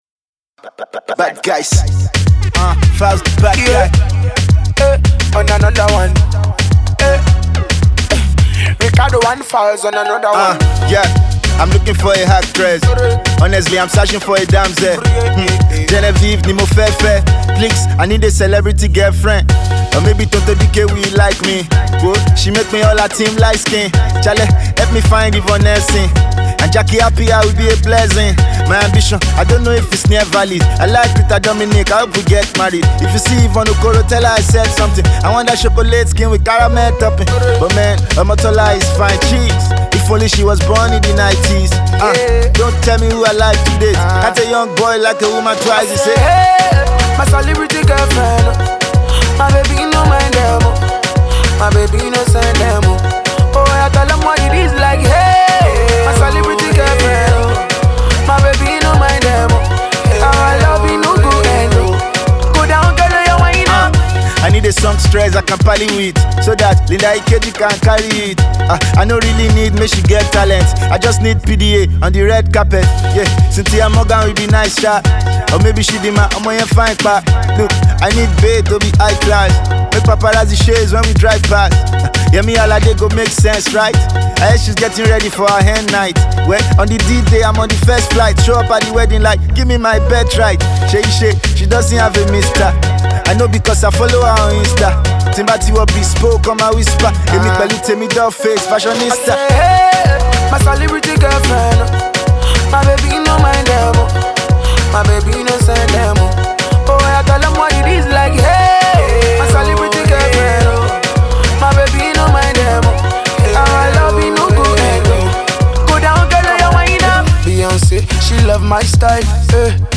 trademark comic flow
dancehall-type beat